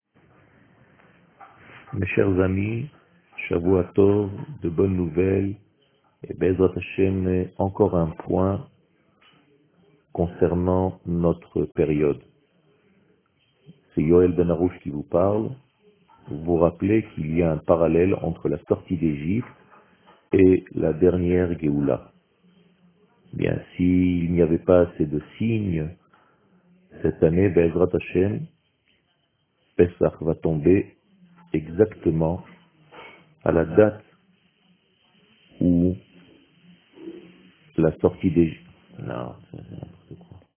שיעור מ 21 אפריל 2020